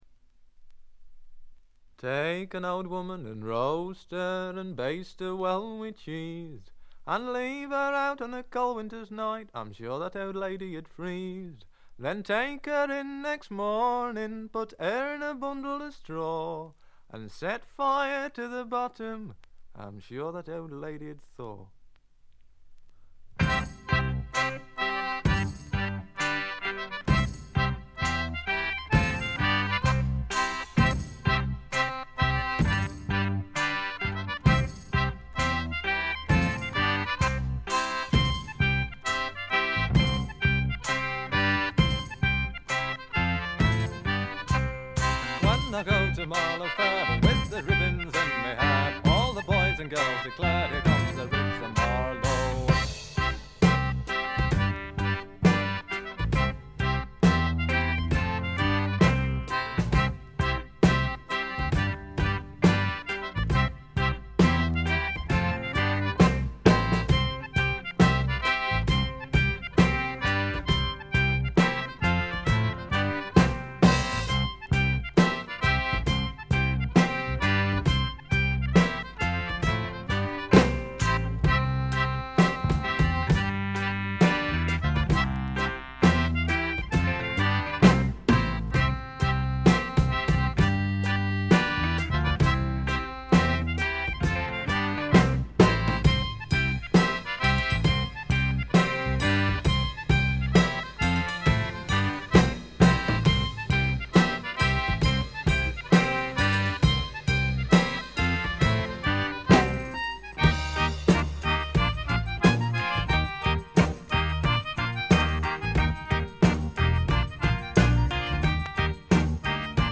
部分試聴ですが、わずかなノイズ感のみ、良好に鑑賞できると思います。
1st同様に豪華メンバーによる素晴らしいエレクトリック・トラッドです。
試聴曲は現品からの取り込み音源です。